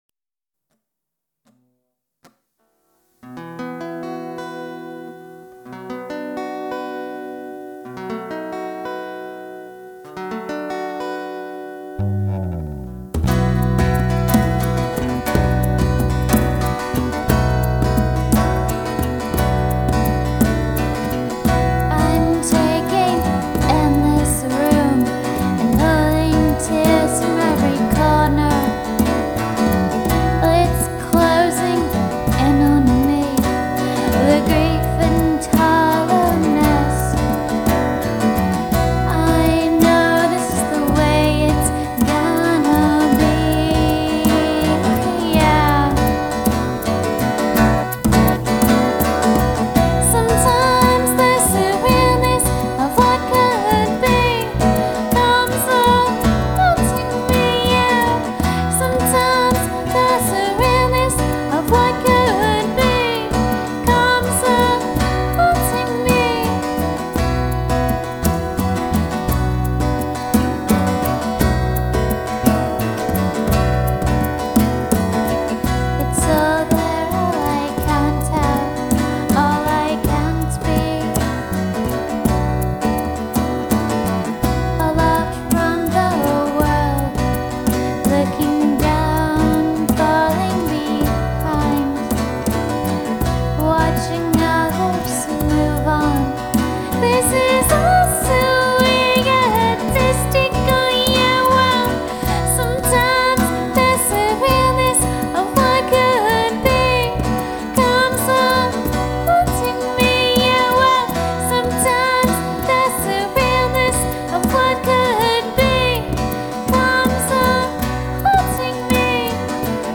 guitars and vocals recorded and done by myself